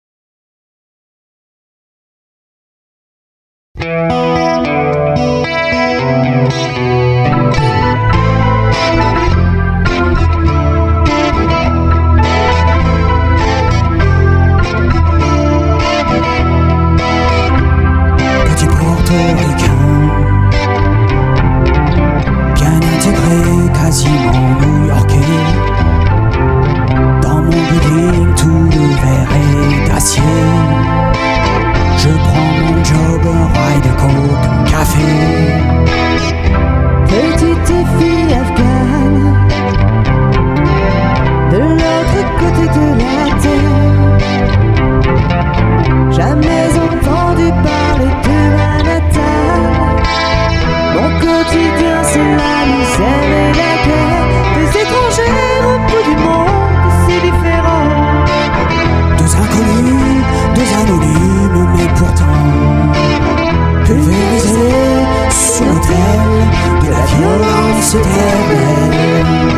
Studio Côtier, Frontignan, France.
Guitare
Chant, Choeurs
Claviers, Chant
Basse